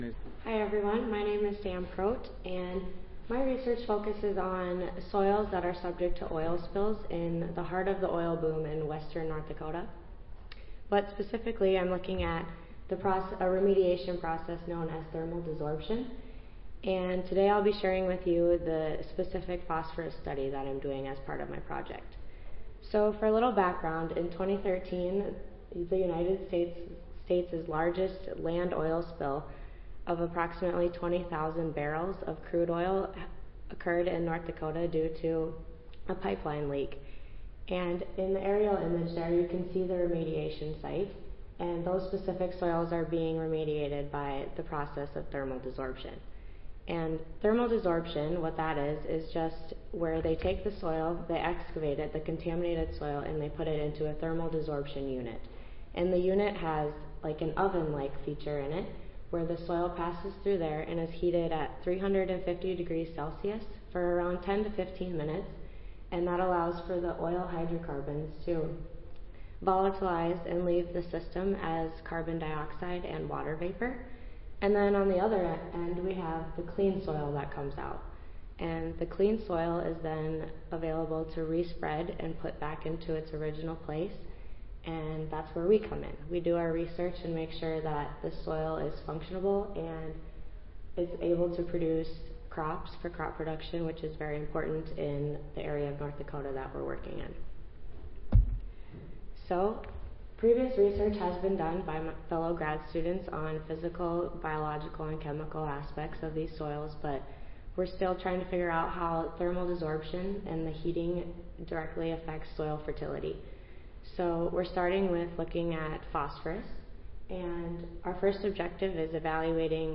Audio File Recorded Presentation